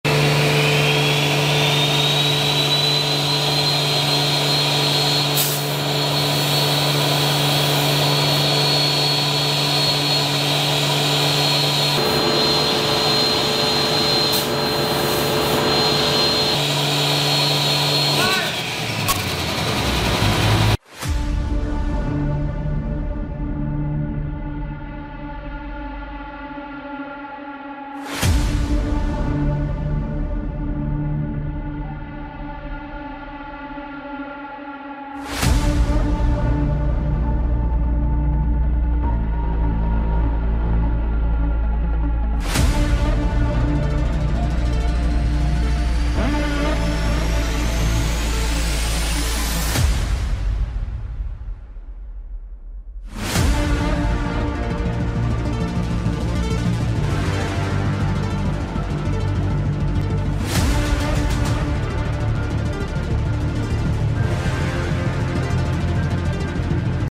See Thru Intercooler In Slow sound effects free download
See Thru Intercooler In Slow Motion - (Dyno Run High Boost)